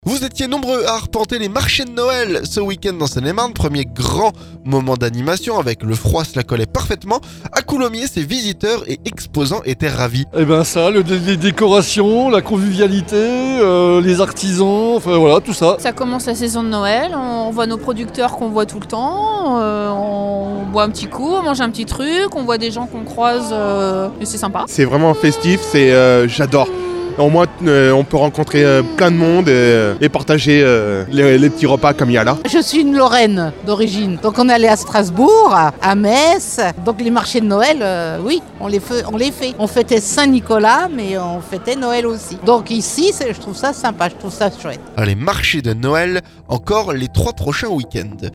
A Coulommiers, ces visiteurs et exposants étaient ravis.